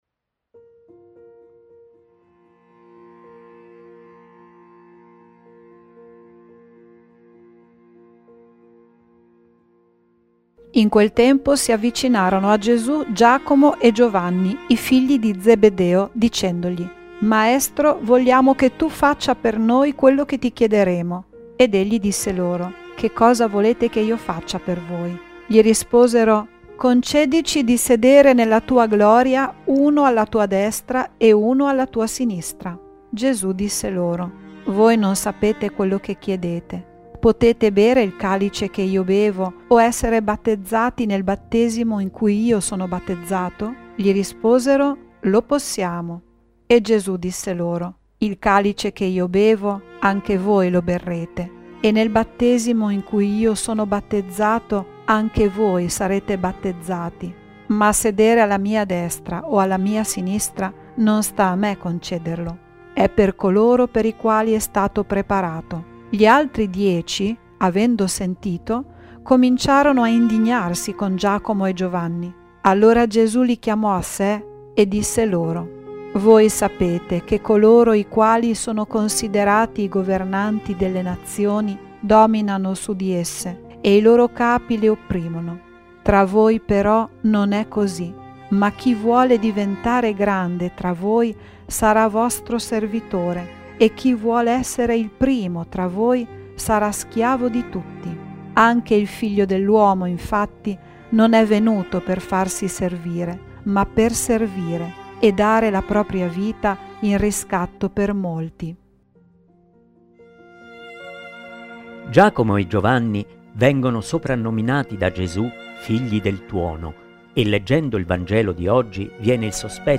E' la riflessione dell'arcivescovo di Modena-Nonantola e vescovo di Carpi, mons. Erio Castellucci sul Vangelo di Marco (Mc 10, 35-45 )